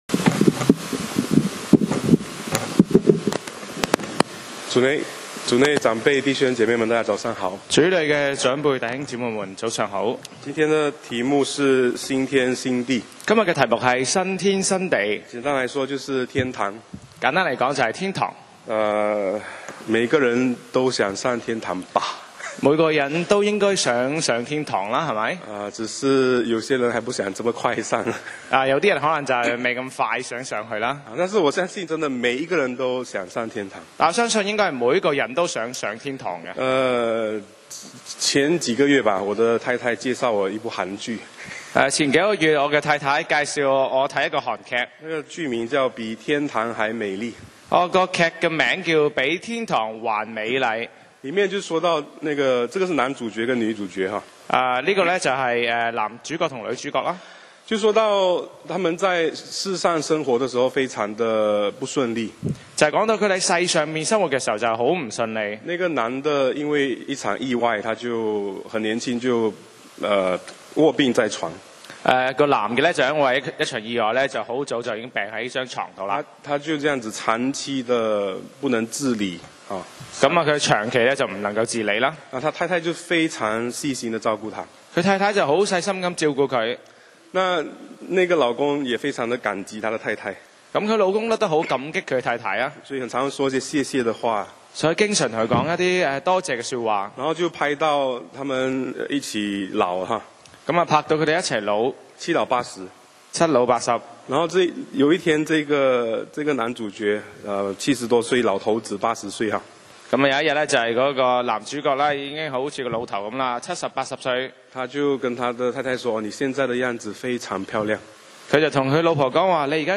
講道 Sermon 題目 Topic：新天新地 經文 Verses：启示录 21:1-6. 1我又看见一个新天新地。